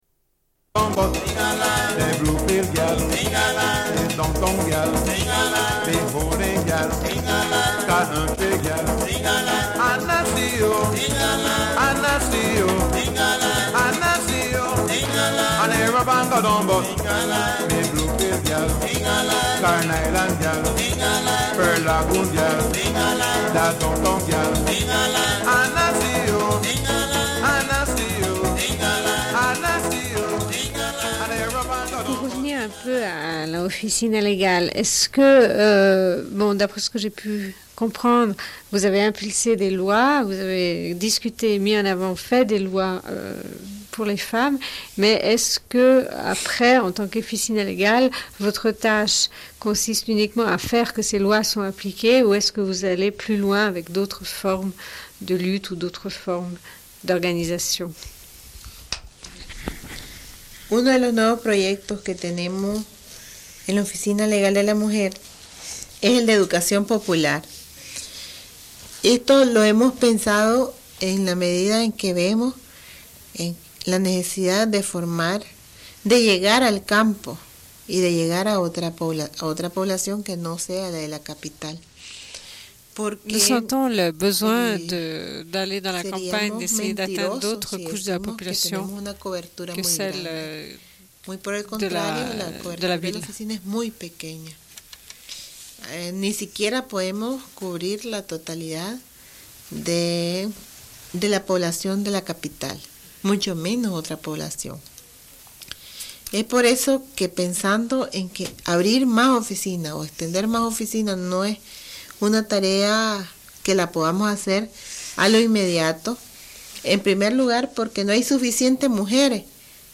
Une cassette audio, face B47:21
Prise de parole d'une internationaliste qui était au Nicaragua en 1983. Diffusion d'une chanson chantée par des enfants d'une crèche au Nicaragua.